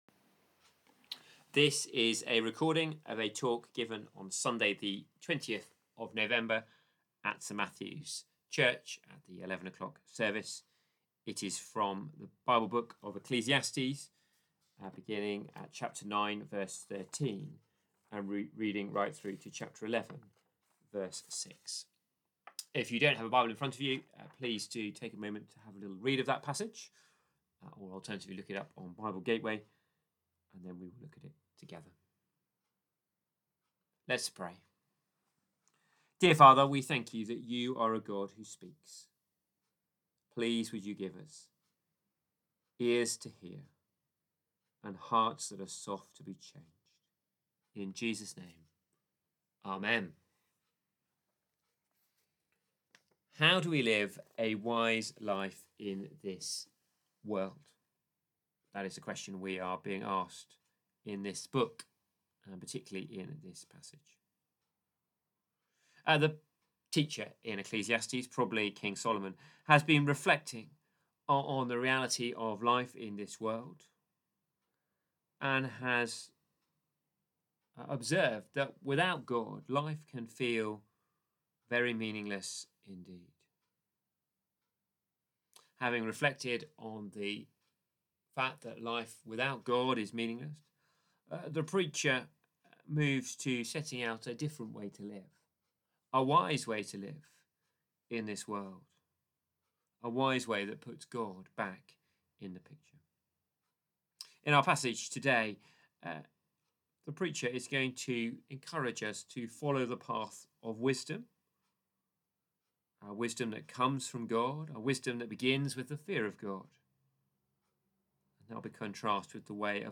Passage: Ecclesiastes 9:13-11:6 Service Type: Morning Worship « Wisdom